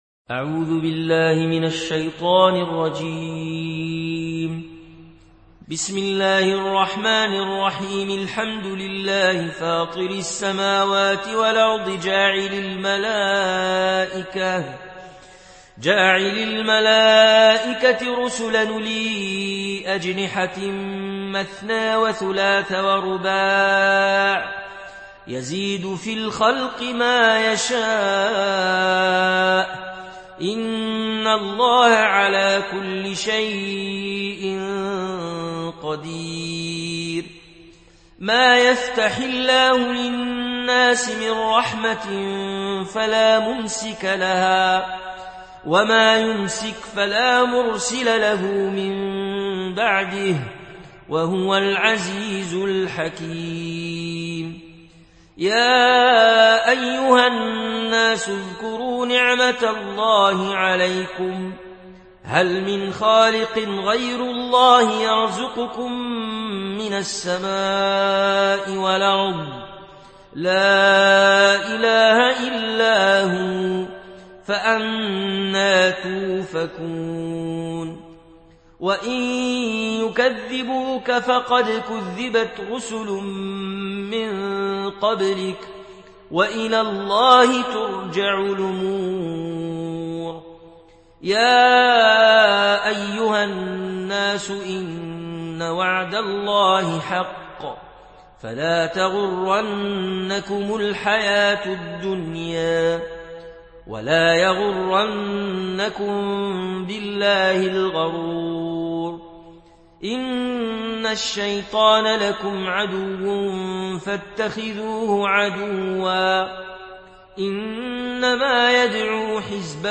Riwayat Warsh dari Nafi